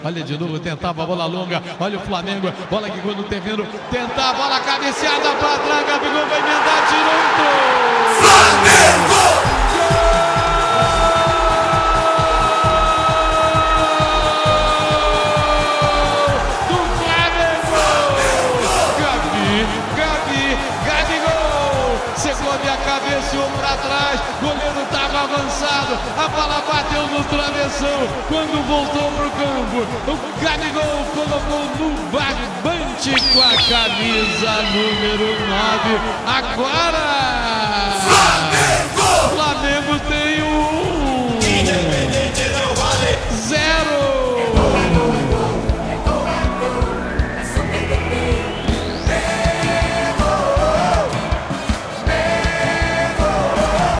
Ouça os gols do título do Flamengo na voz de José Carlos Araújo